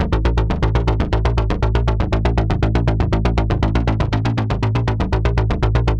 Index of /musicradar/dystopian-drone-samples/Droney Arps/120bpm
DD_DroneyArp4_120-A.wav